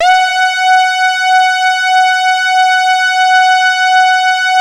78-TARKUS F#.wav